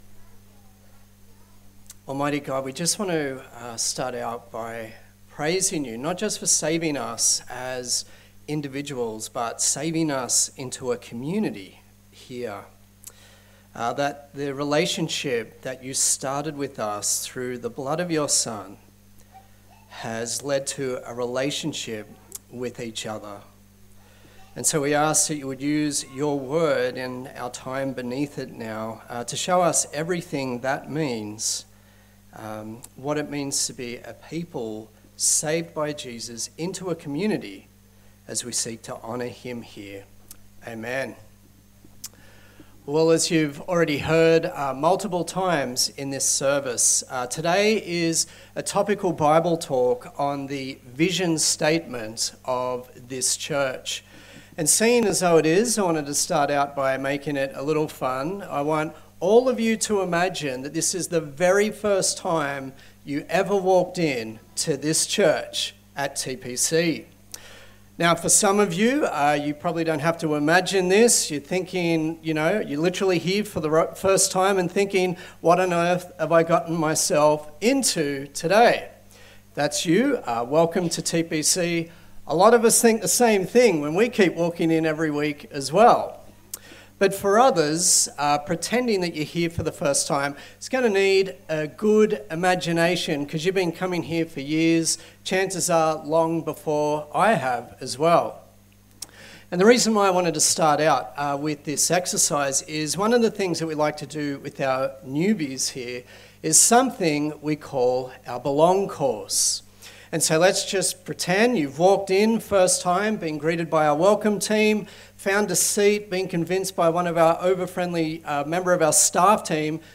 A sermon on the Vision of Tuggeranong Presbyterian Church.
Colossians 1:1-23 Service Type: Sunday Service A sermon on the Vision of Tuggeranong Presbyterian Church.